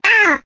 yoshi_oof2.ogg